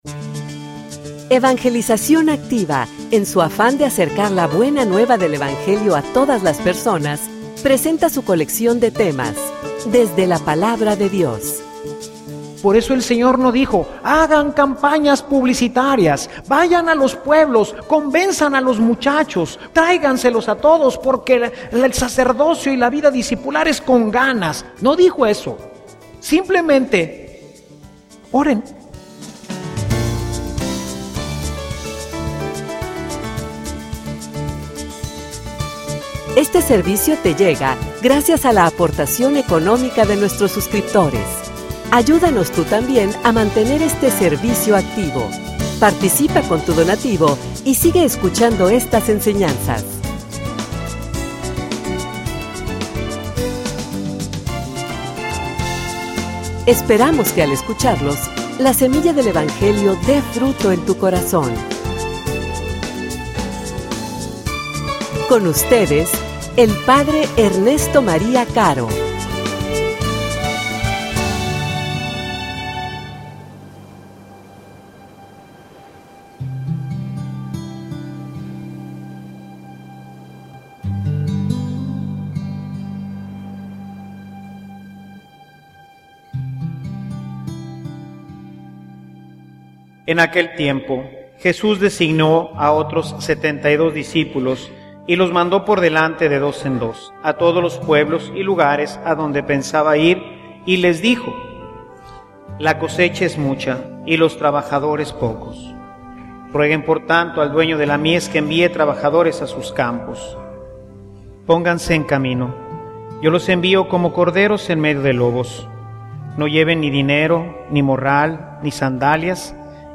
homilia_Senor_manda_operarios_a_tu_mies.mp3